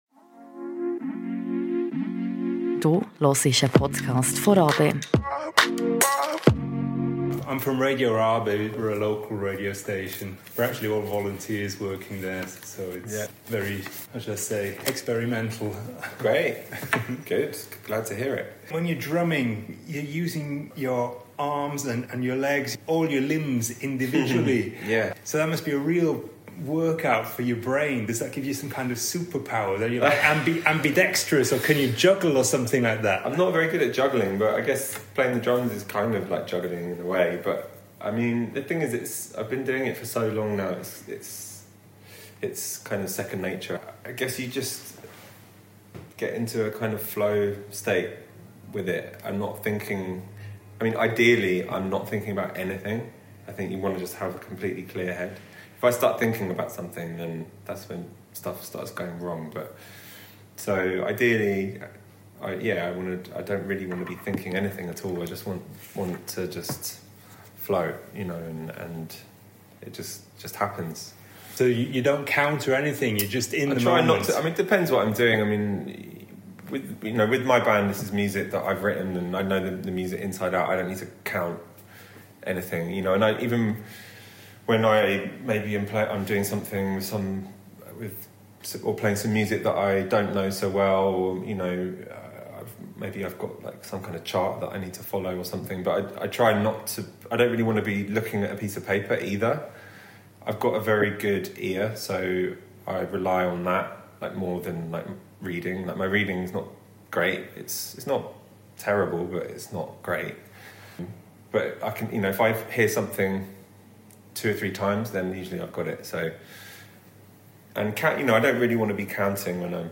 The sound quality is not super, as we met on really short notice and I had to record on my old phone.